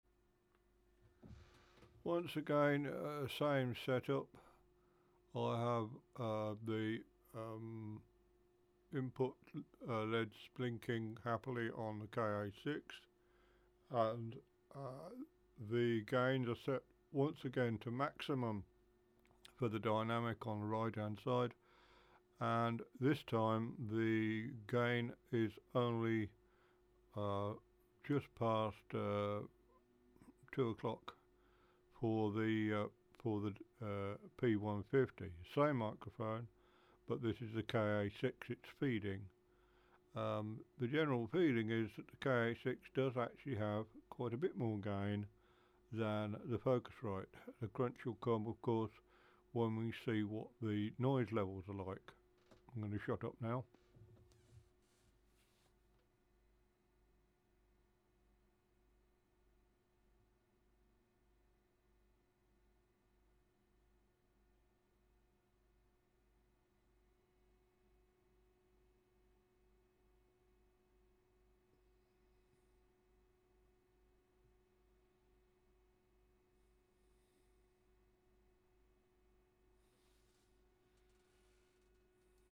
The 8i6 has more gain than the KA6 but might be a tad noisier? In any case I was speaking at about 200mm off mics and you would reduce that distance by a third to a quarter for a dynamic as a rule.
Note that a lot of the noise is PC fan rumble at 100Hz'ish and a HPF at 150Hz gives a considerably better noise floor.
frite stereo 01.mp3